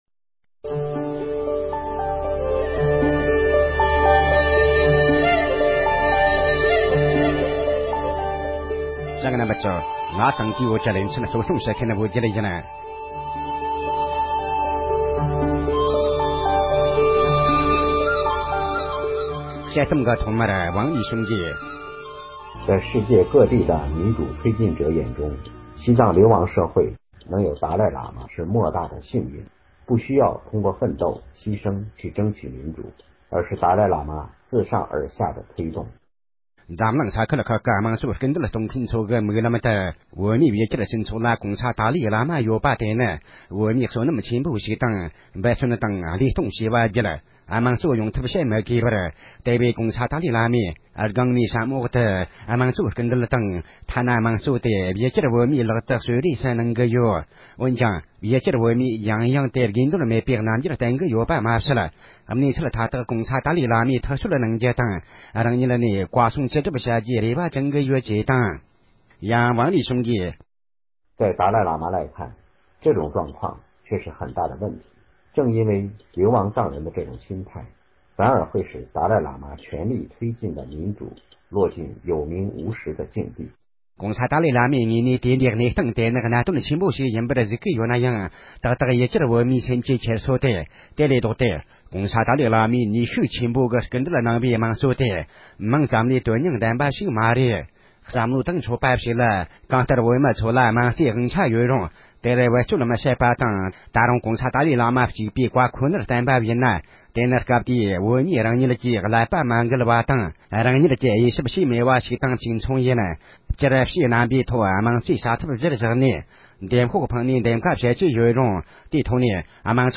ཕབ་བསྒྱུར་དང་སྙན་སྒྲོན་ཞུས་པ་ཞིག་གསན་རོགས་གནང་༎